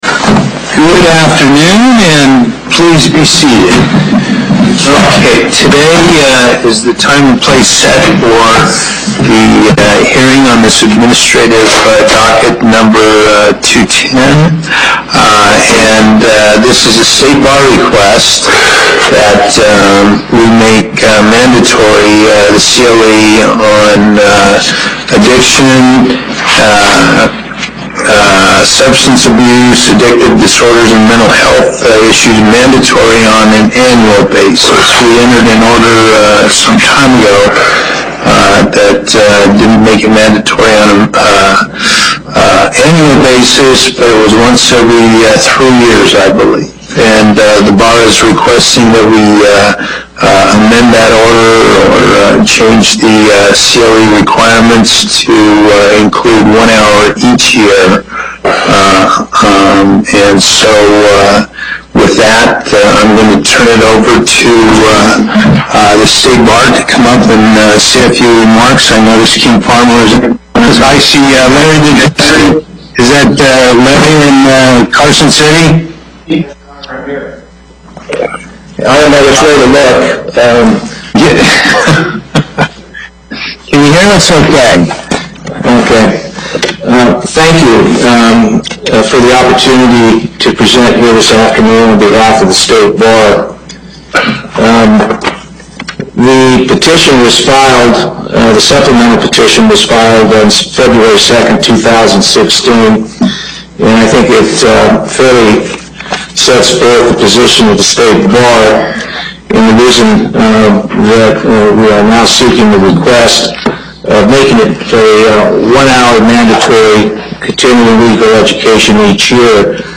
Location: Las Vegas Before the En Banc Court